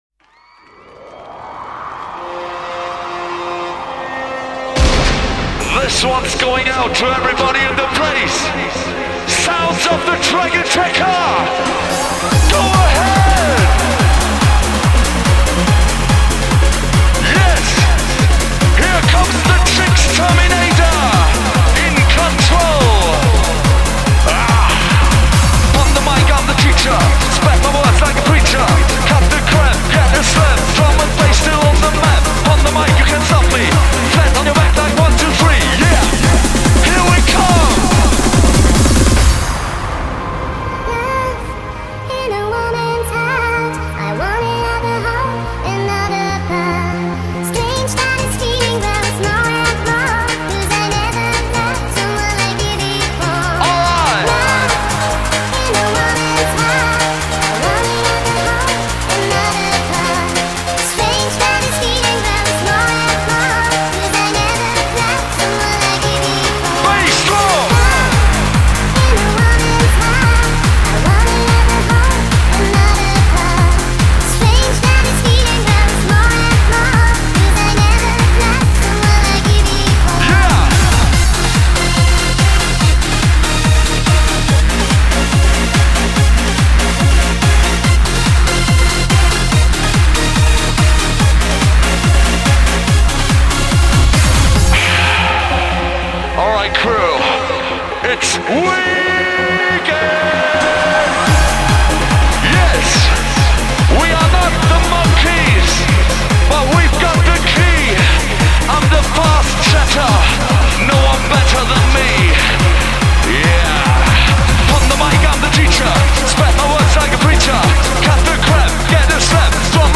Genre: Dance , Pop